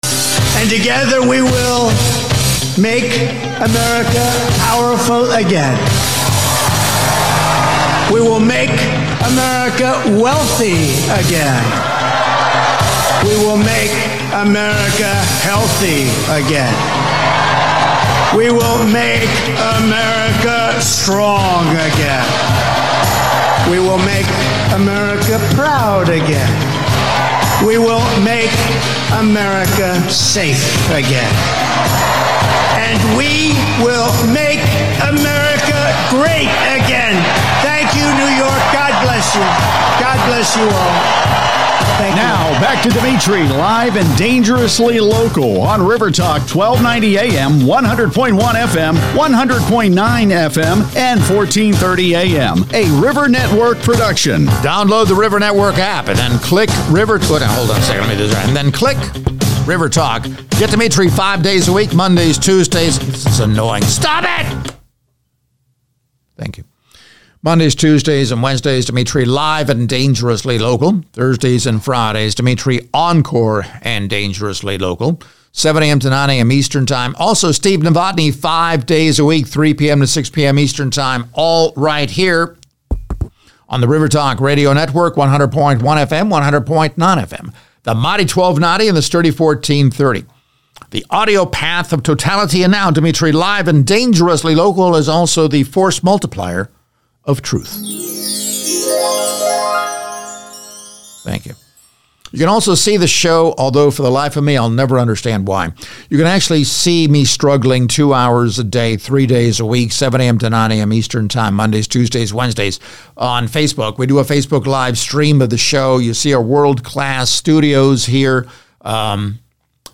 talk radio